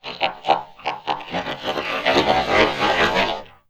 ALIEN_Communication_09_mono.wav